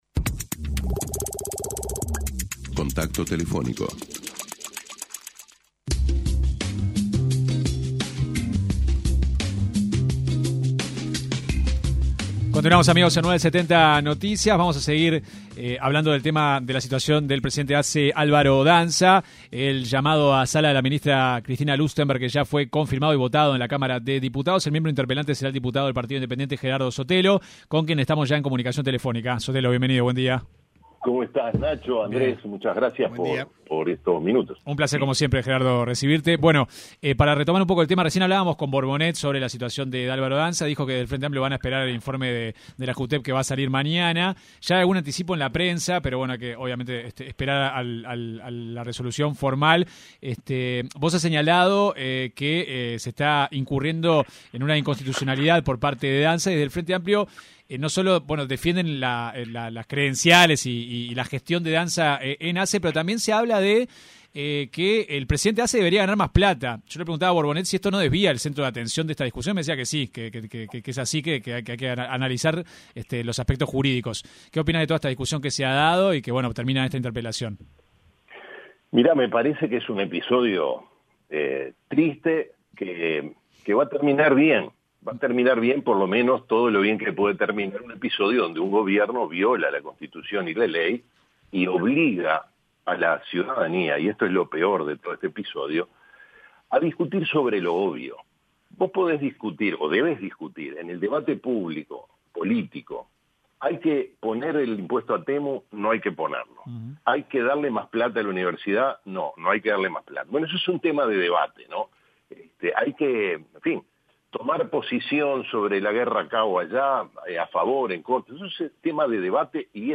El diputado del Partido Independiente, Gerardo Sotelo, cuestionó en una entrevista con 970 Noticias, el informe jurídico que el Ministerio de Salud Pública le solicitó al estudio Delpiazzo en diciembre del año pasado.